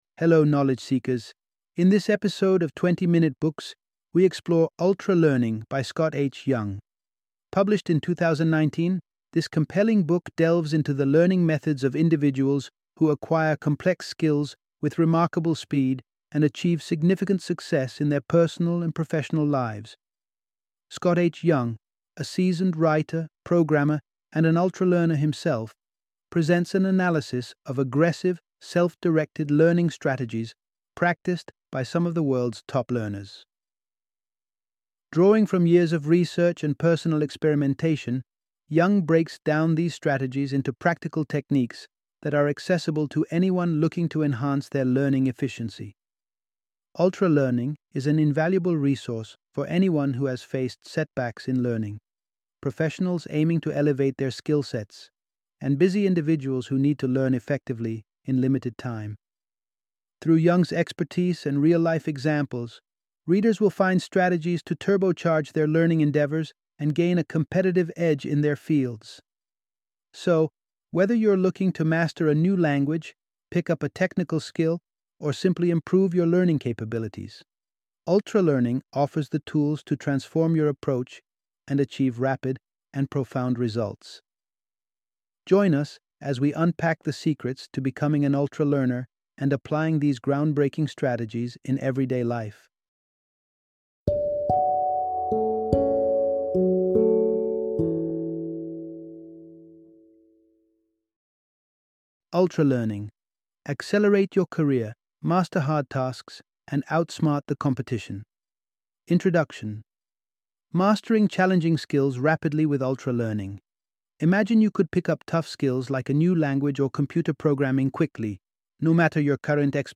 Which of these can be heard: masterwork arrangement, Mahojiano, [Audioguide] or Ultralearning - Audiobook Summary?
Ultralearning - Audiobook Summary